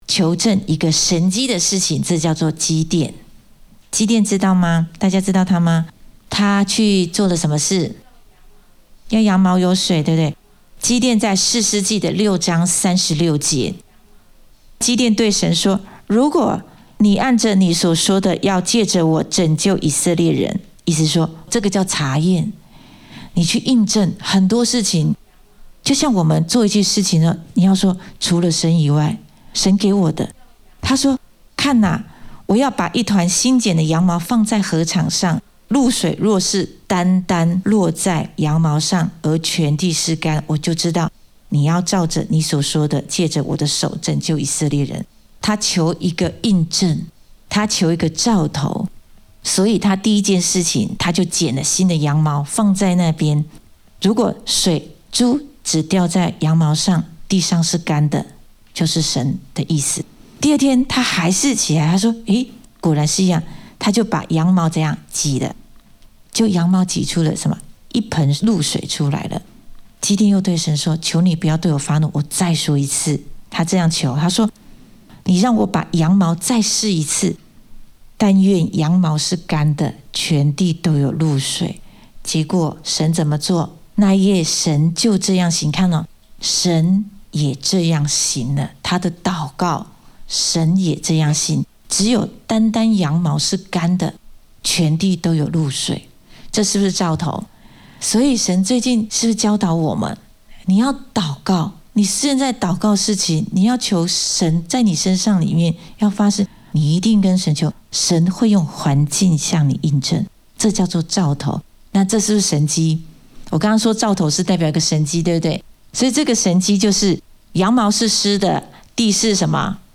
in 主日信息